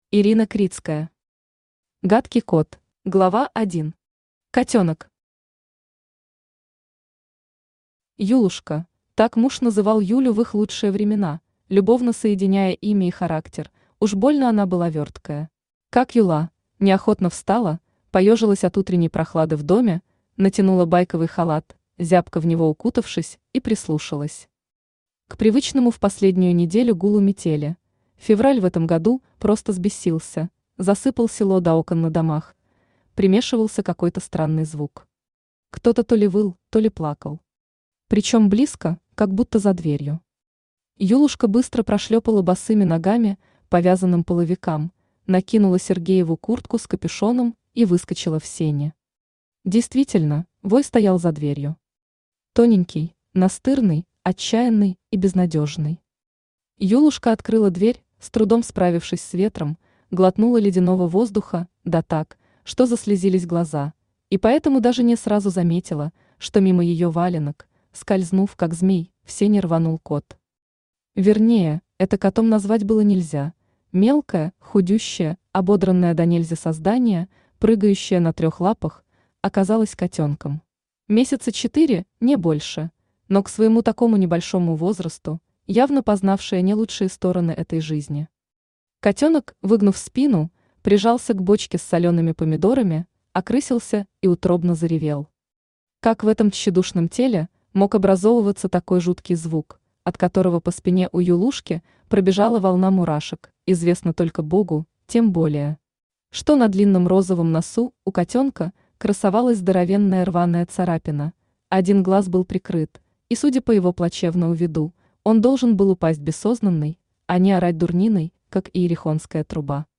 Аудиокнига Гадкий кот | Библиотека аудиокниг
Aудиокнига Гадкий кот Автор Ирина Критская Читает аудиокнигу Авточтец ЛитРес.